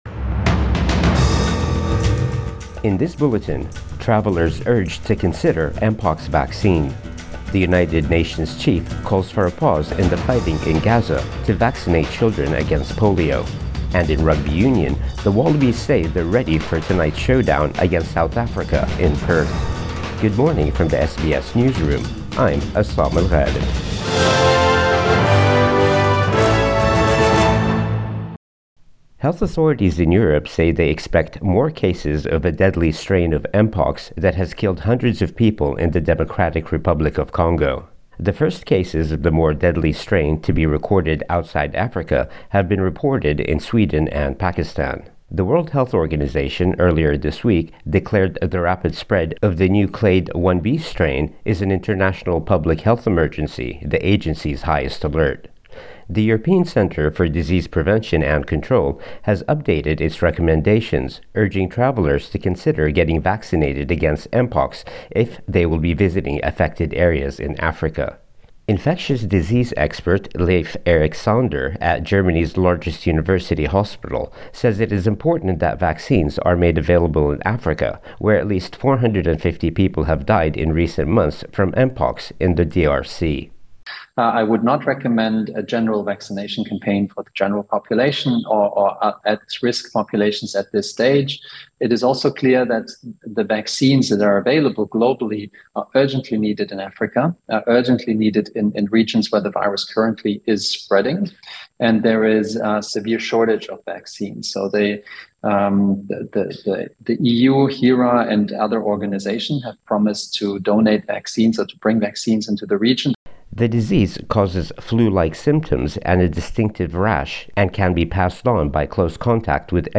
Morning News Bulletin 17 August 2024